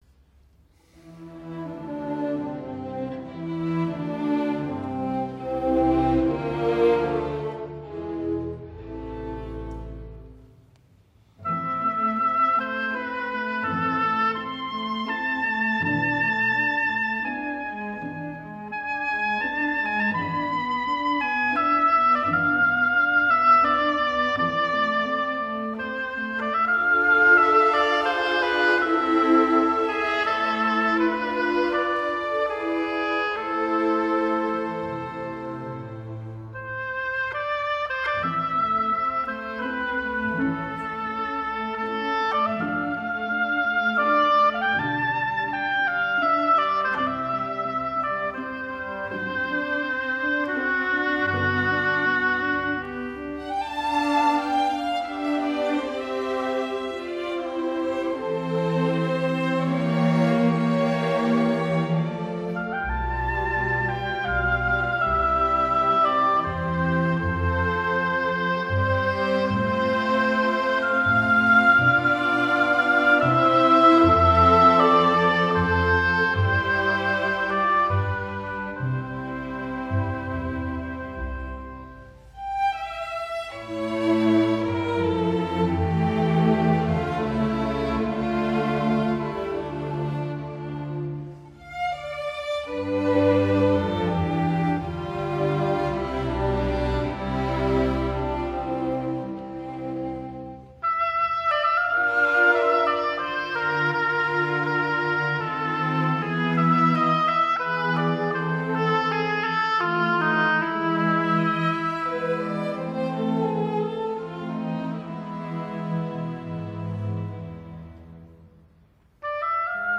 Concerto pour hautbois - 3e mvt : Sicilienne